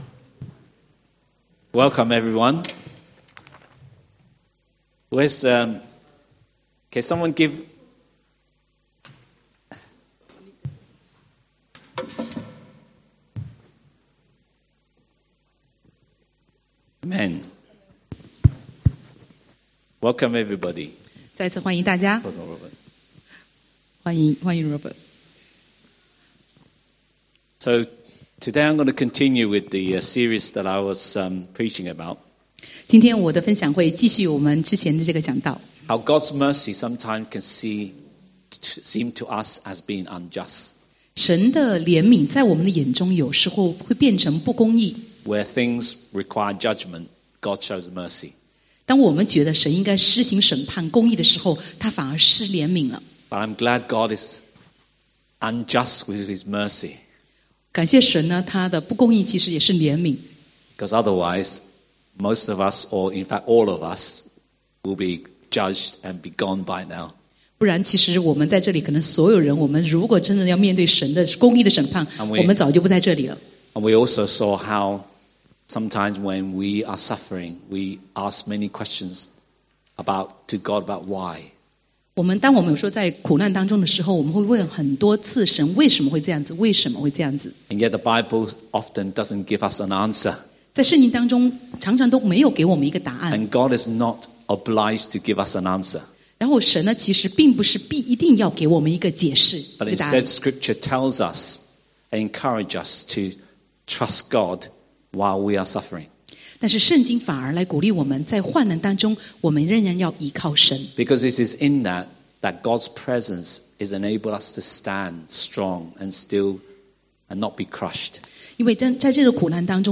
East Ham Church Sermon